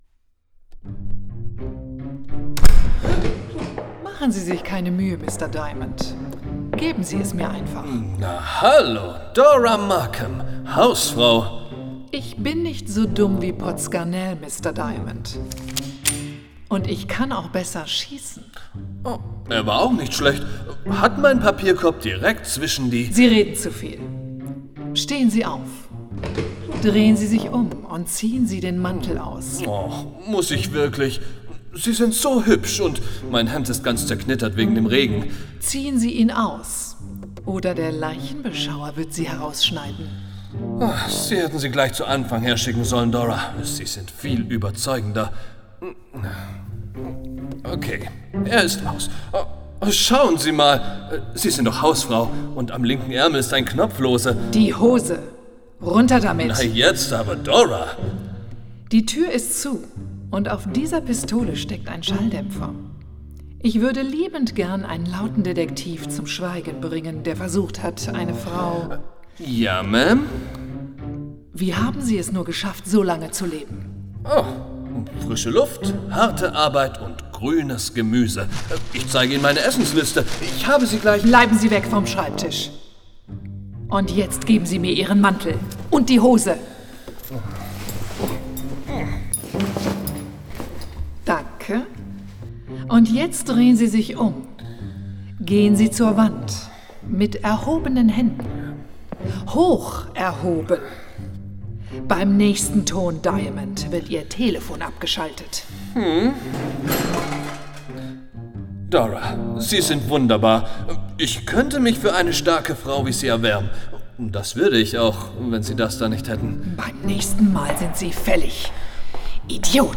Voiceover & Sprecher
Als Sprecher für Voiceover, Werbung, Imagefilme, Eventvideos, Hörbücher und Hörspiele bringe ich Inhalte authentisch und emotional auf den Punkt – ruhig oder dynamisch, seriös oder lebendig, passend zu deinem Projekt.